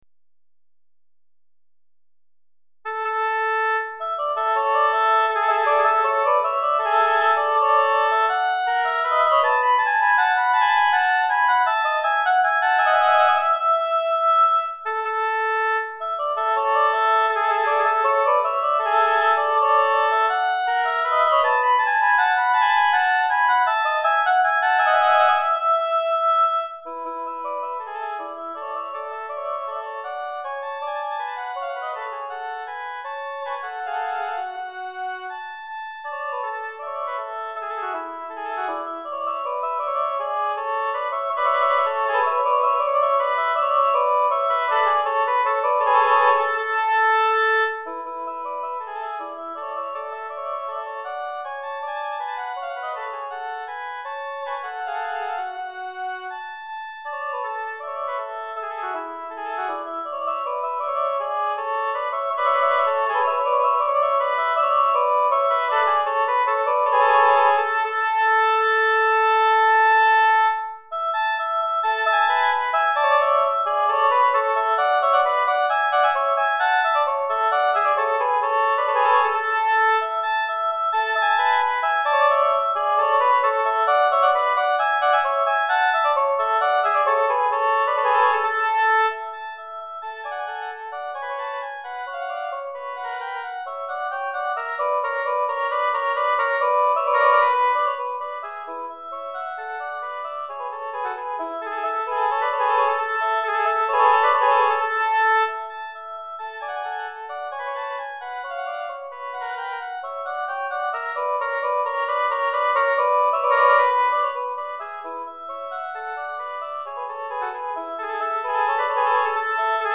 2 Hautbois